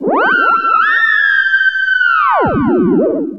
From The Cutting Room Floor Jump to navigation Jump to search File File history File usage Metadata Zombieville1997-THEREM4.ogg  (Ogg Vorbis sound file, length 3.4 s, 82 kbps) Unused Audio file from ZV 97. This file is an audio rip from a(n) Windows game.